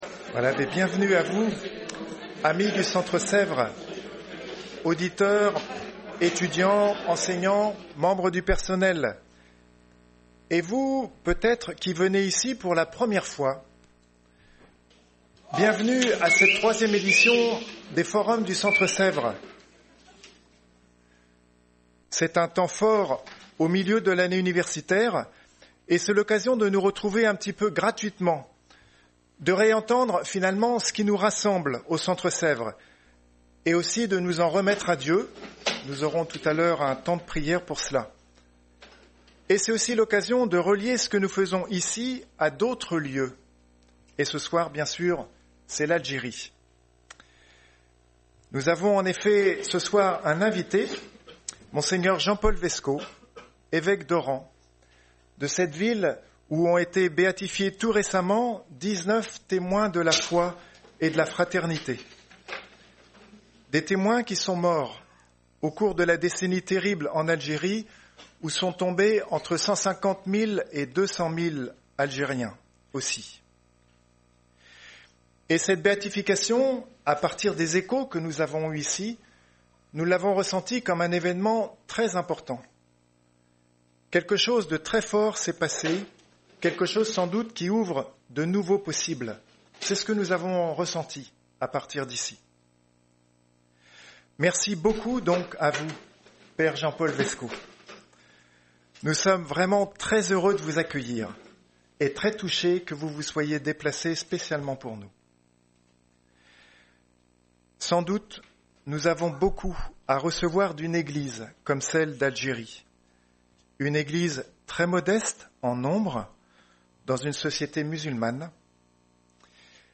Mgr. Jean-Paul VESCO, évêque d’Oran (Algérie) était le Grand Invité lors de la 3e édition des Forums du Centre Sèvres le 18 janvier 2019.
il a ensuite été interrogé par des étudiants sur le thème Témoins du Christ en terre d’Islam à la suite de la béatification des 19 martyrs d’Algérie en décembre 2018.